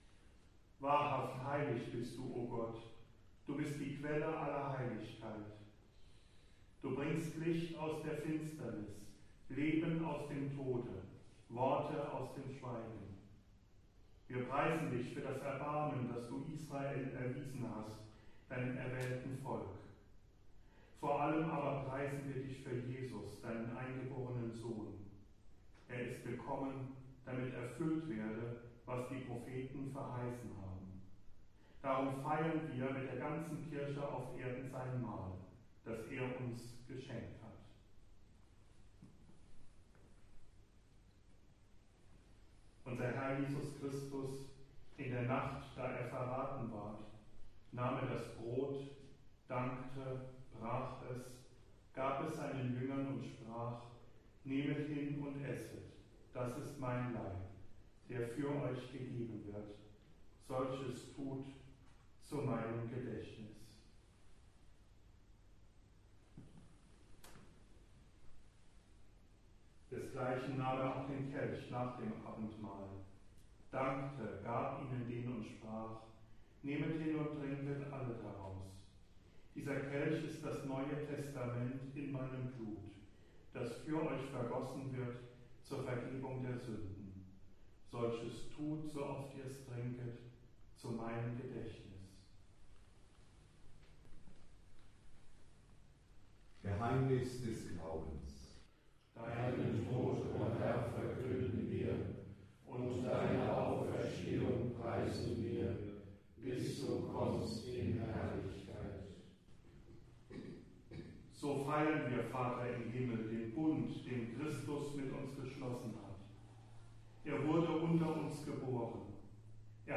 B I T T E   B E A C H T E N S I E : Diese Gesänge sind nur zu Übungszwecken hier eingestellt.
Sie sind nicht von einer professionellen Schola gesungen und auch nicht unter Studiobedingungen aufgenommen.